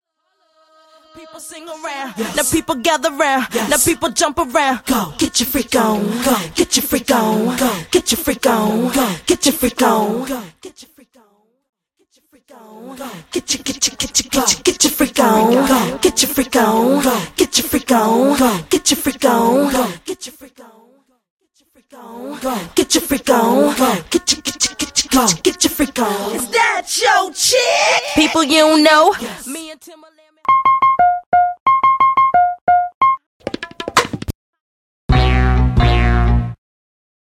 Studio All Bassline Stem
Studio Percussion & Drums Stem
Studio Strings & Keys Stem